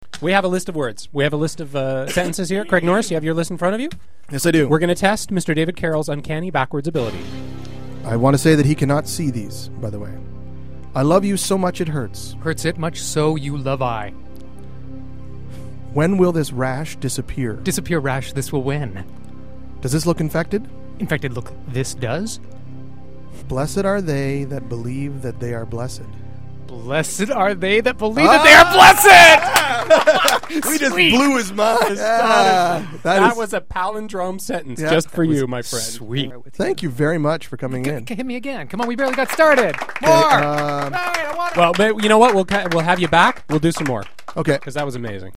I can talk backwards.
But listen – in that radio show appearance, I only flipped the words in each sentence around, and repeated them back in the opposite order.